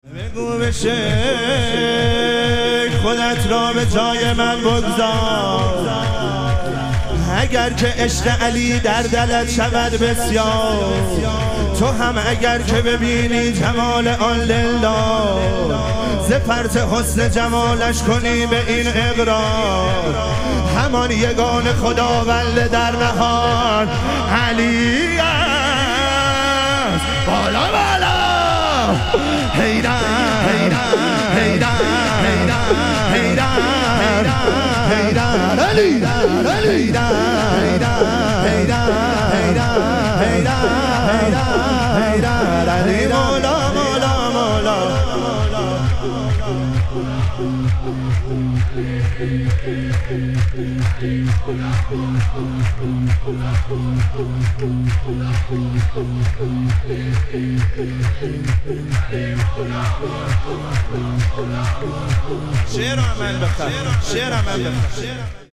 ظهوروجود مقدس امام هادی علیه السلام - شور